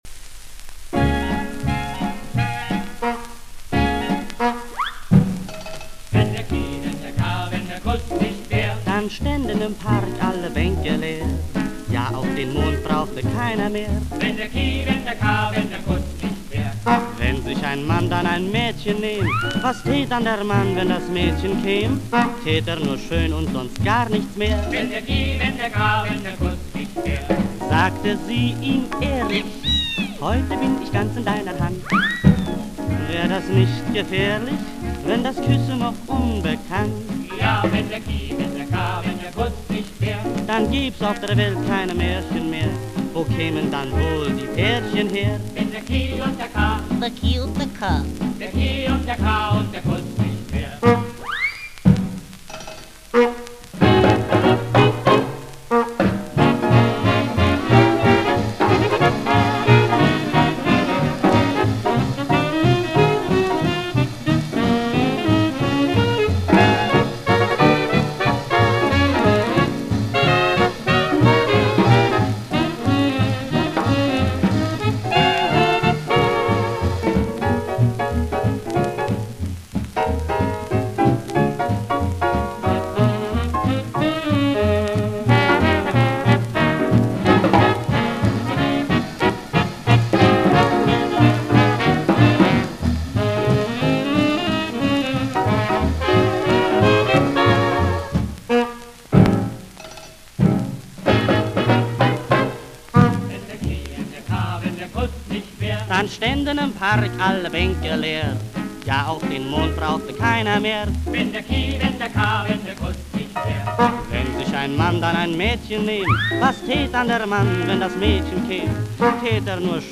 редкостный немецкий свинг
фокстрот